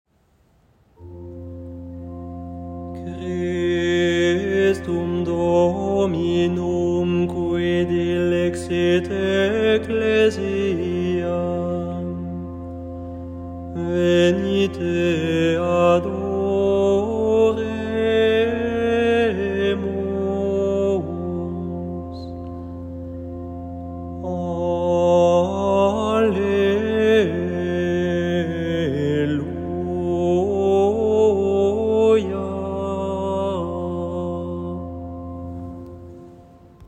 Antienne invitatoire (temps pascal) : Christum Dominum [partition LT]